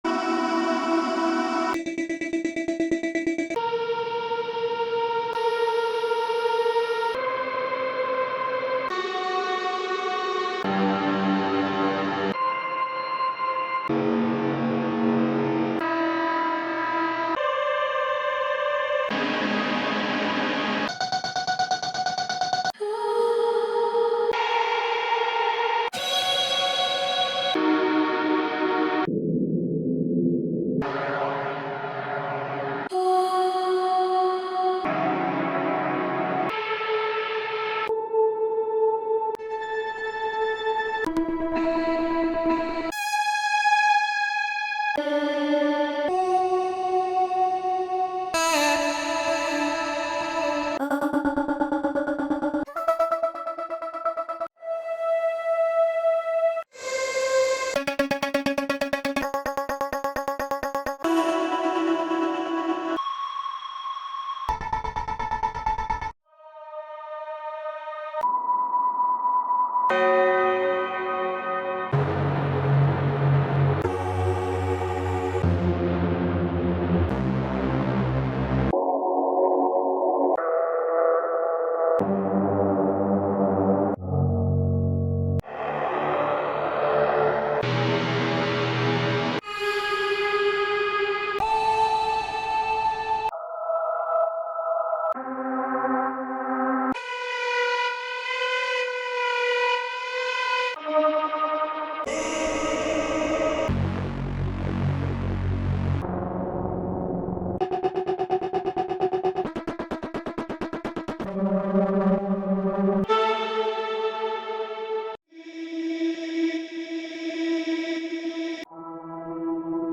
是一套丰富而富有氛围感的音色库，旨在为任何曲目增添深度并填补空白。
包含 100 个氛围采样，可让您的曲目听起来更加饱满。